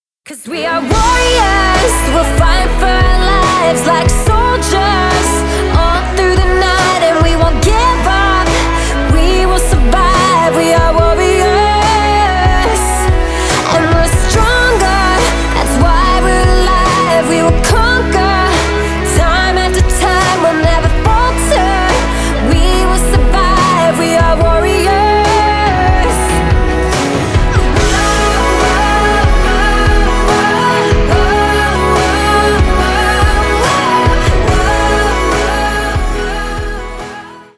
Рок Металл # Поп Музыка